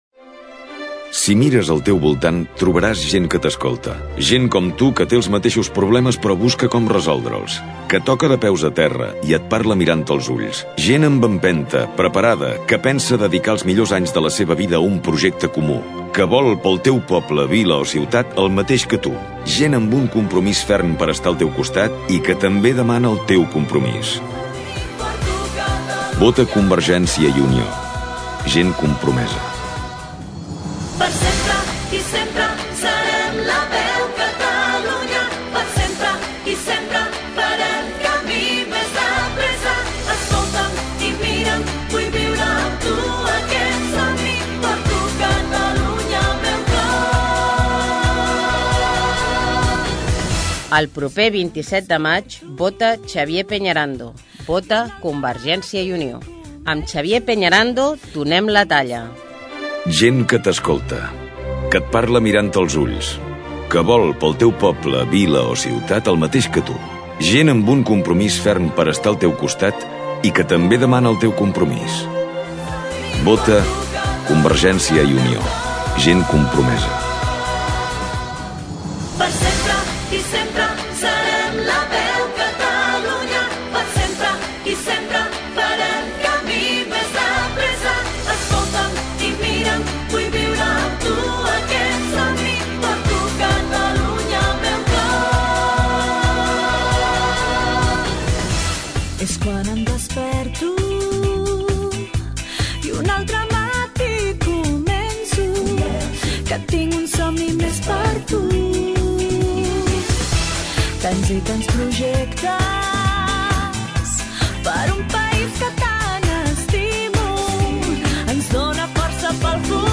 Política MUNICIPALS 2007 - Espai de propaganda de CiU -Política- 14/05/2007 Descarregueu i escolteu l'espai radiof�nic enregistrats per aquest partit pol�tic de Ripollet a l'emissora municipal.